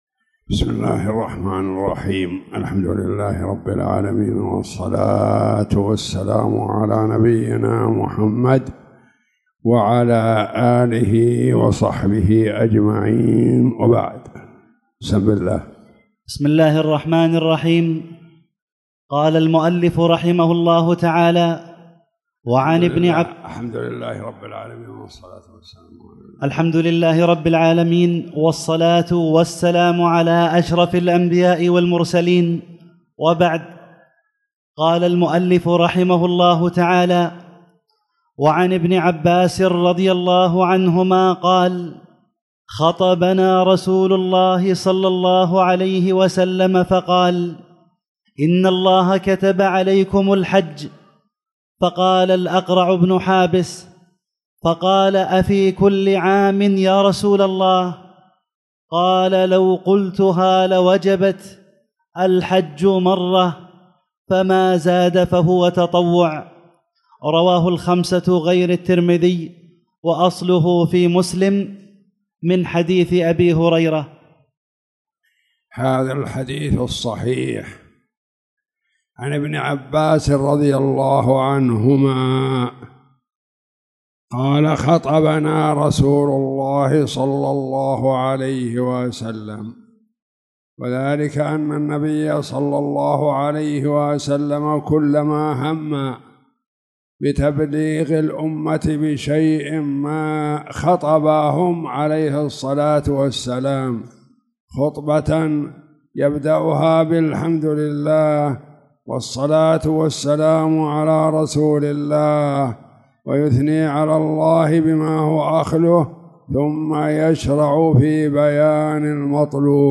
تاريخ النشر ٣ ربيع الثاني ١٤٣٨ هـ المكان: المسجد الحرام الشيخ